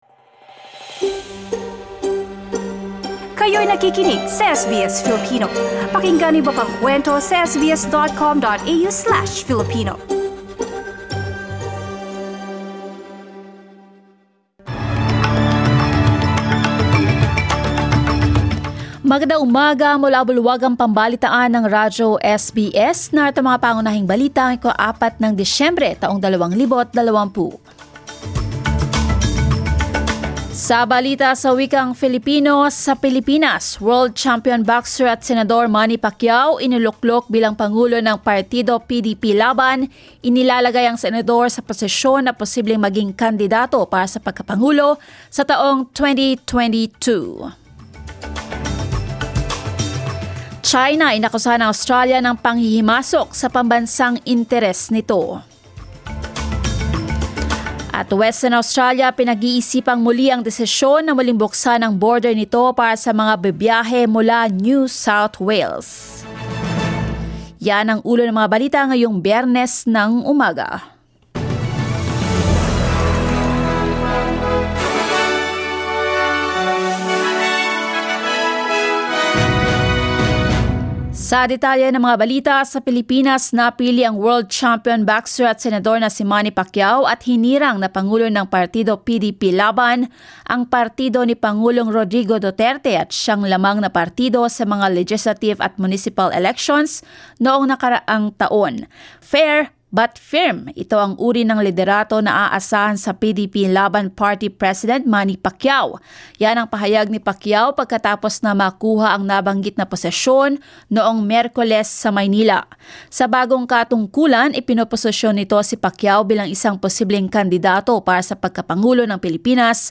SBS News in Filipino, Friday 04 December